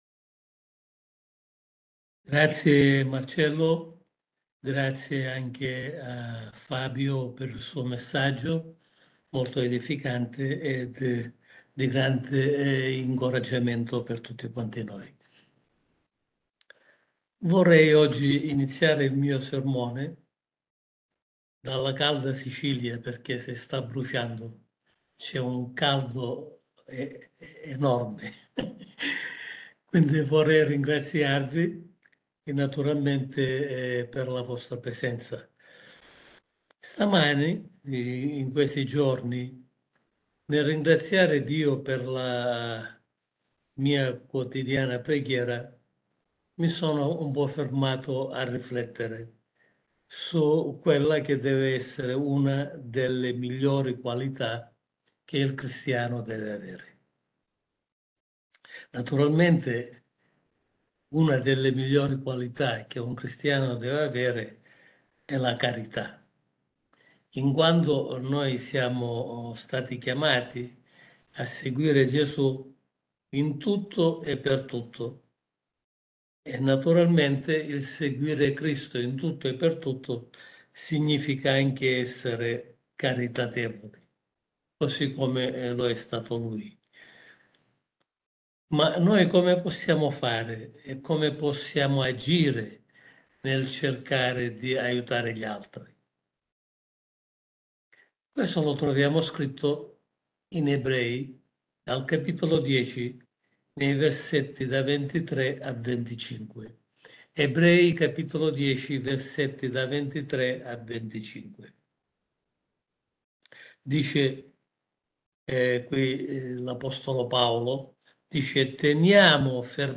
La carità di Dio – Sermone pastorale